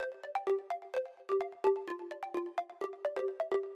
水下合成器
描述：基于切分音和末尾三连音的电子合成器循环。
标签： 128 bpm Electronic Loops Synth Loops 646.17 KB wav Key : Unknown
声道立体声